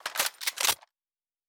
pgs/Assets/Audio/Sci-Fi Sounds/Weapons/Weapon 15 Reload 3.wav at master
Weapon 15 Reload 3.wav